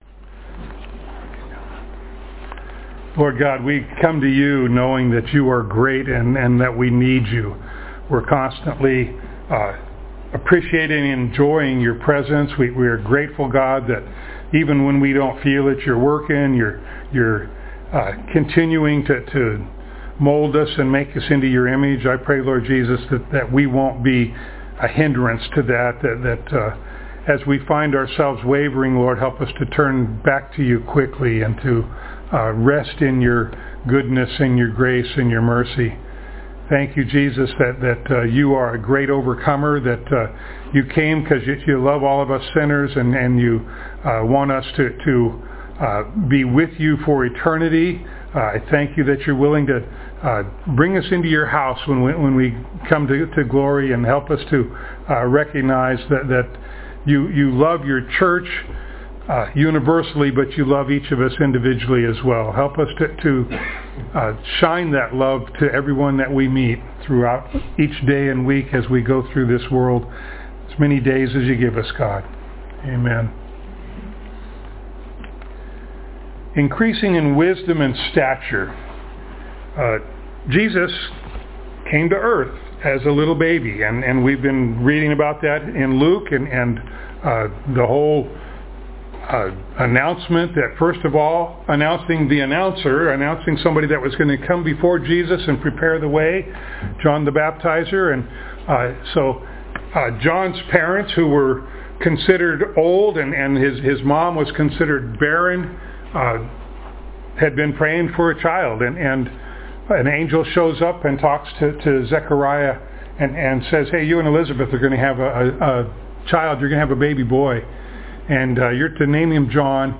Luke Passage: Luke 2:39-52, Matthew 2:1-23 Service Type: Sunday Morning